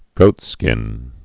(gōtskĭn)